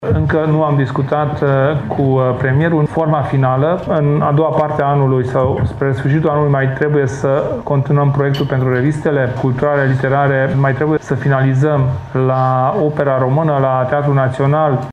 Nici Ministerul Culturii nu va pierde bani la rectificarea bugetară, afirmă ministrul de resort Kelemen Hunor. El susţine că sunt în derulare mai multe proiecte pentru care este nevoie de fonduri: